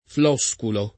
flosculo [ f l 0S kulo ]